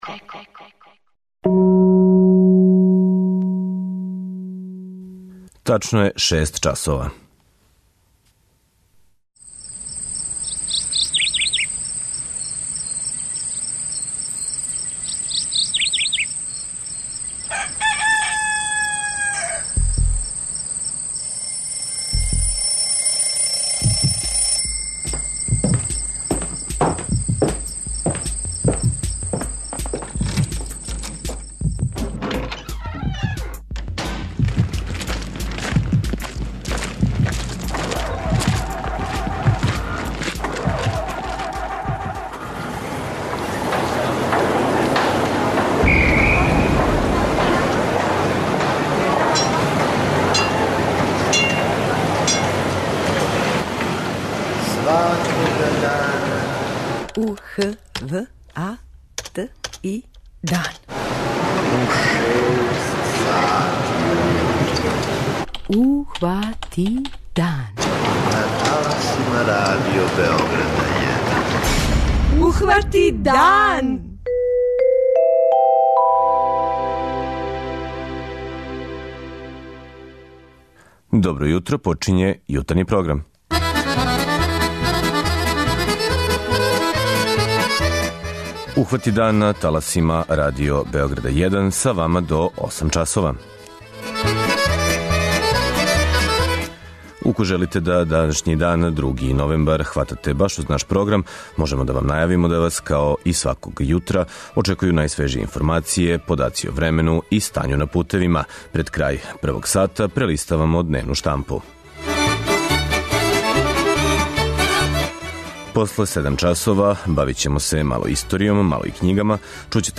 У редовној рубрици "Књига Солидарности" своје виђење поезије у данашњем свету даје познати писац и академик Матија Бећковић.
преузми : 57.32 MB Ухвати дан Autor: Група аутора Јутарњи програм Радио Београда 1!